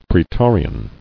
[prae·to·ri·an]